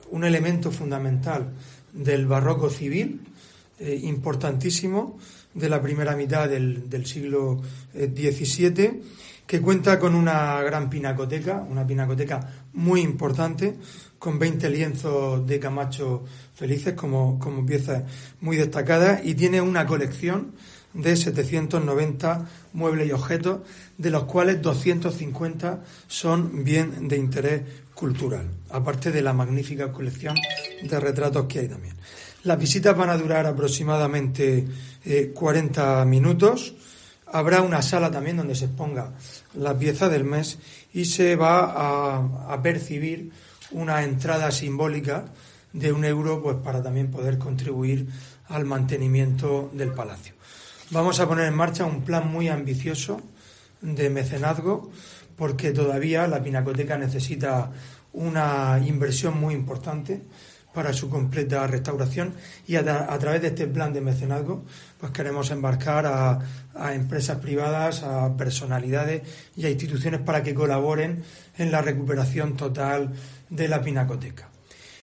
Fulgencio Gil, alcalde de Lorca